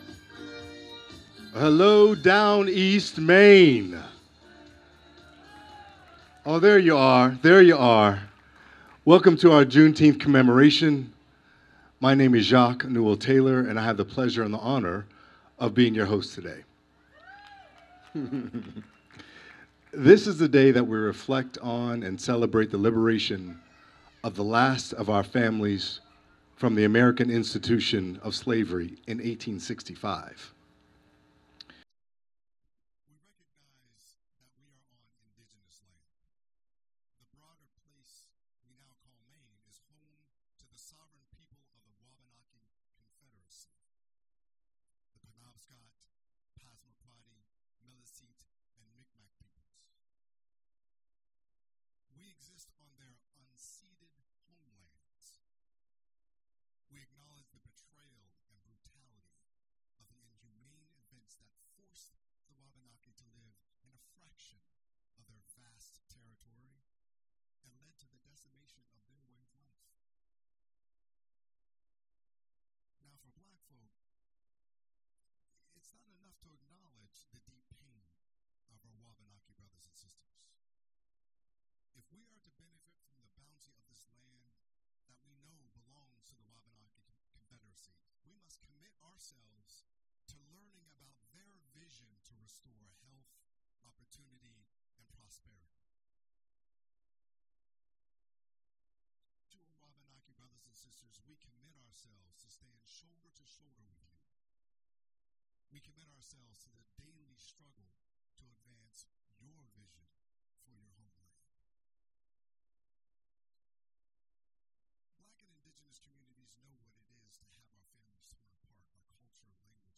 Recording of the Juneteenth Downeast Commemoration Event at Knowlton Park, Ellsworth, Thursday, June 19 2025.